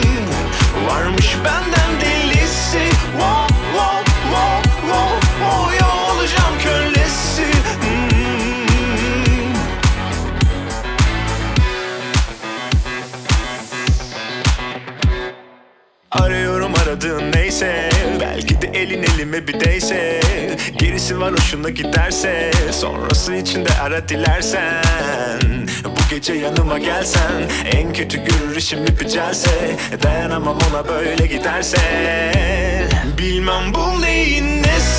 # Turkish Alternative